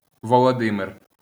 なお、ロシア語名では「ヴラジーミル、ウラジーミル、ヴラヂーミル、ウラヂーミル」（Владимир [vlɐˈdʲimʲɪr] (  音声ファイル)）、ウクライナ語名では「ヴォロディムィル、ウォロディミル、ウォロディメル、ウォロディメィル[1]」（Володимир[wɔɫɔˈdɪmer] (